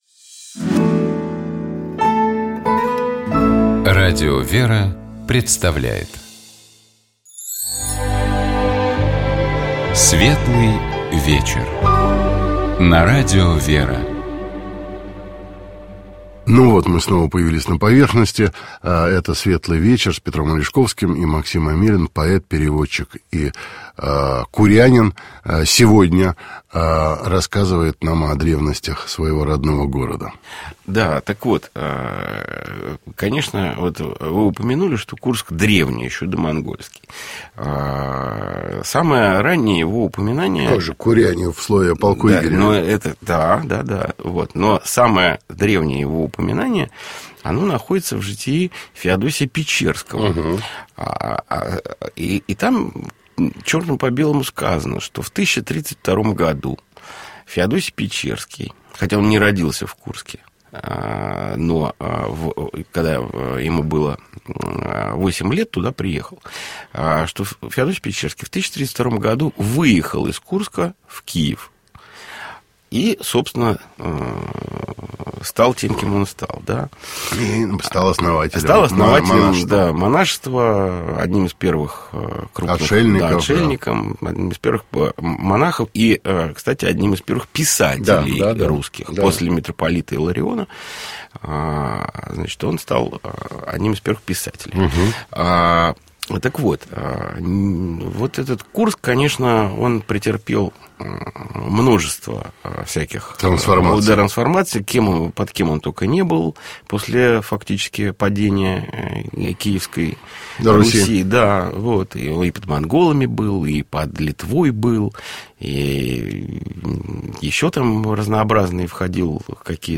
В гостях у Петра Алешковского был поэт, переводчик, издатель Максим Амелин.